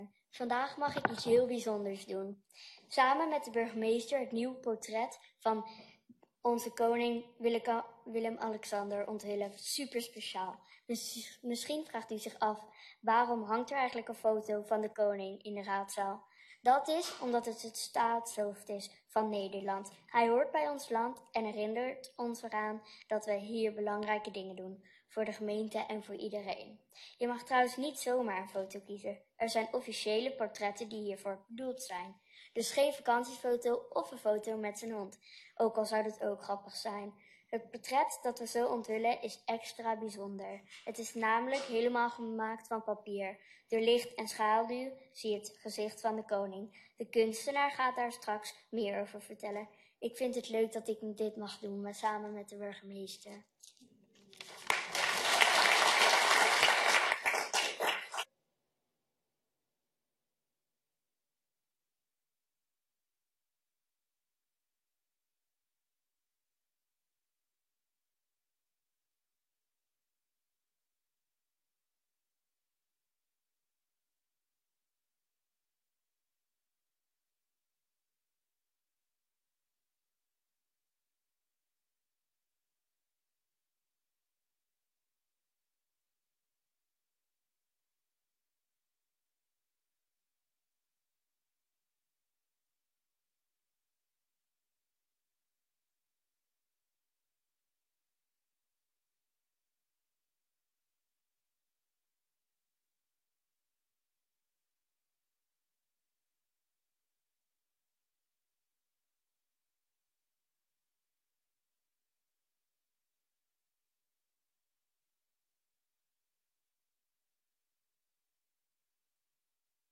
Gemeenteraad 22 april 2025 19:30:00, Gemeente West Betuwe
Locatie: Raadzaal